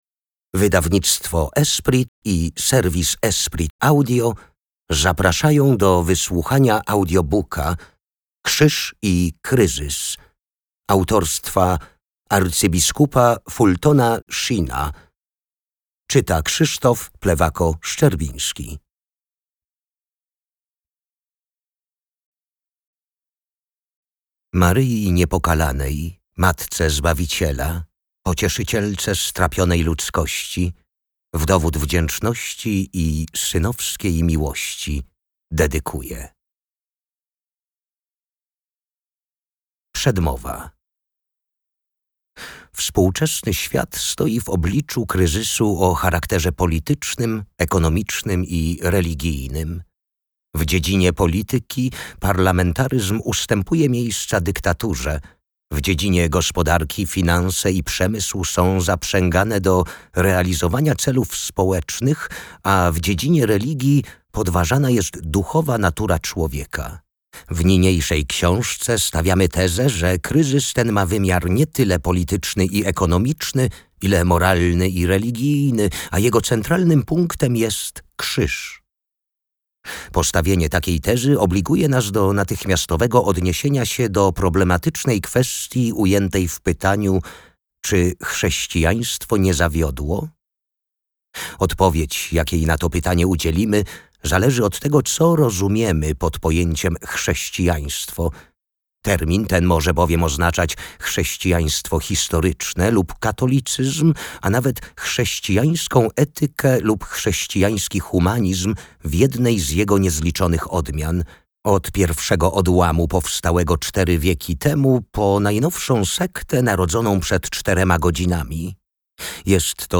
Audiobook “Krzyż i kryzys”, to refleksja o rozdrożach współczesnego świata. Arcybiskup Fulton Sheen, znany katolicki filozof i apologeta, wnikliwie analizuje sedno kryzysu moralnego, który dzisiaj trawi społeczeństwo.